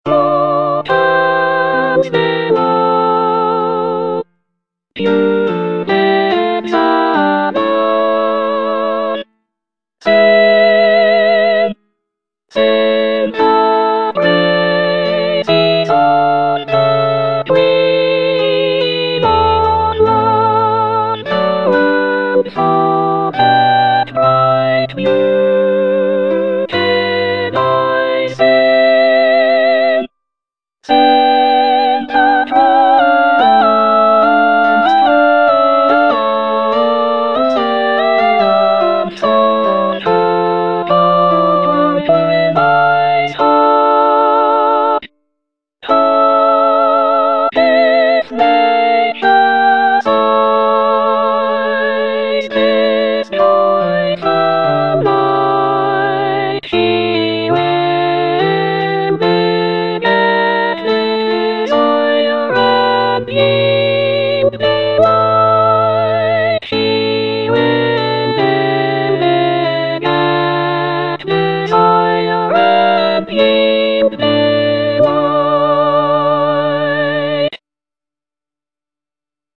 J. BLOW - SELECTION FROM "VENUS AND ADONIS" Chorus of the Graces - Soprano (Emphasised voice and other voices) Ads stop: auto-stop